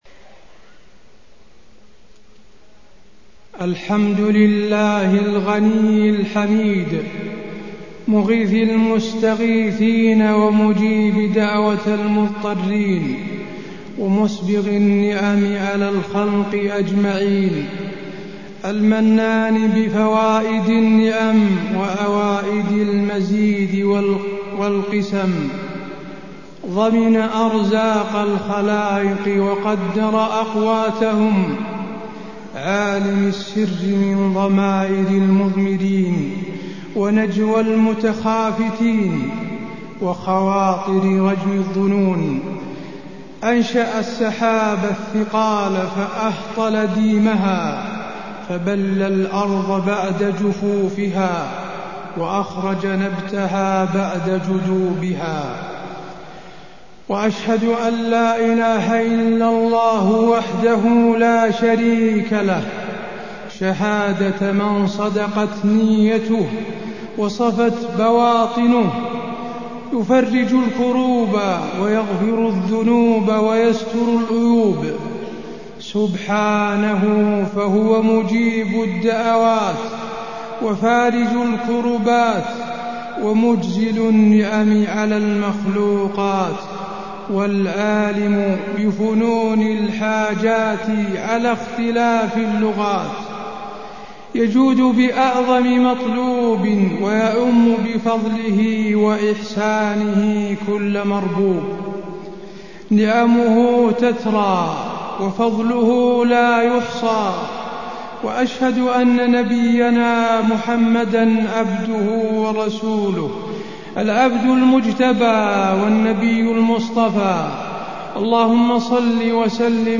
خطبة الاستسقاء - المدينة- الشيخ حسين آل الشيخ
المكان: المسجد النبوي